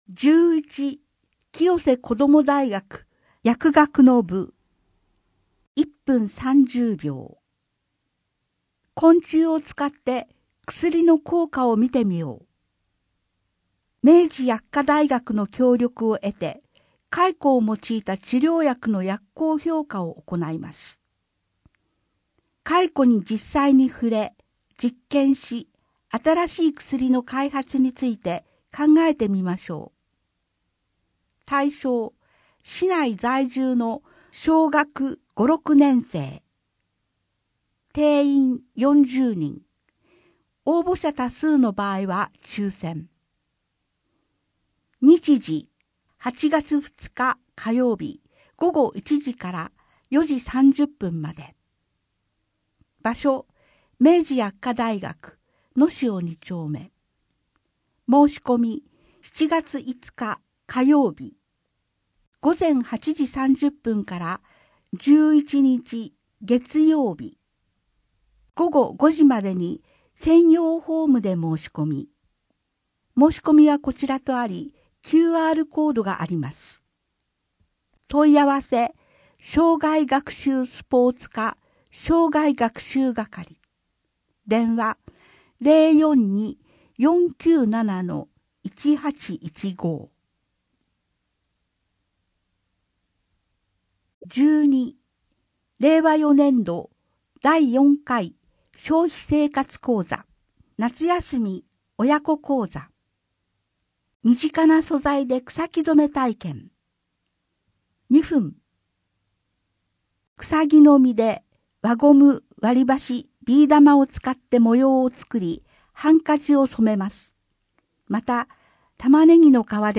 声の広報 声の広報は清瀬市公共刊行物音訳機関が制作しています。